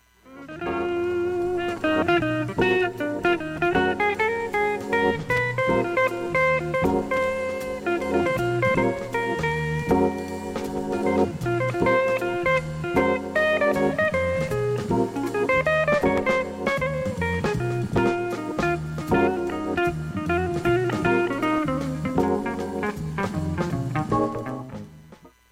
ほか音質良好全曲試聴済み。
ごくかすかなプツ（サッ）が5回と2回出ます。
ほか5回までのかすかなプツ1箇所
◆ＵＳＡ盤'70 press Reissue, Stereo
ハードボイルドソウルジャズオルガン